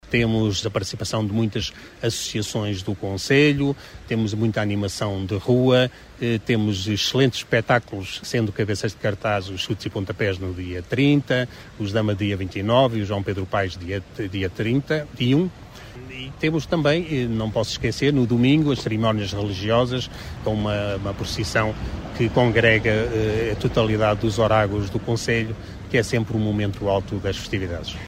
Segundo o autarca será um certame com muita animação. A nível musical o cartaz é composto por nomes como Xutos e Pontapés, DAMA e João Pedro Pais: